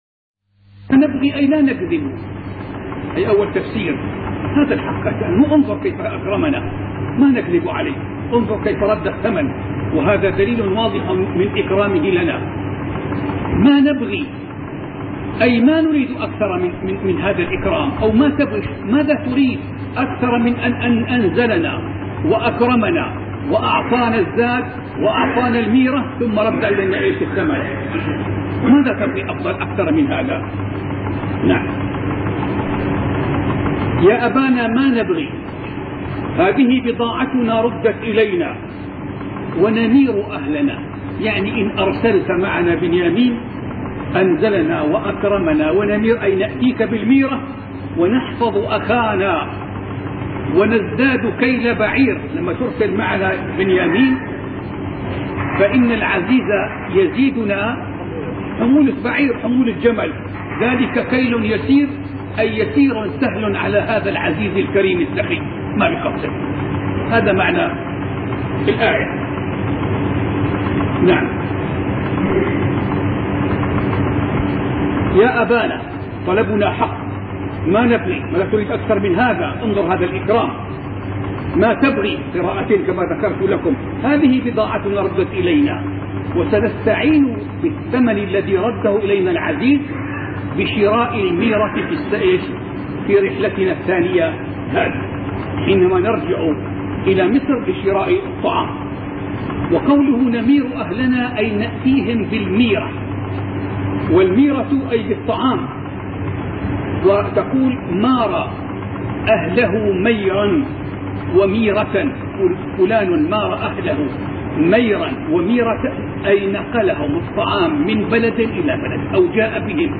سلسلة محاطرات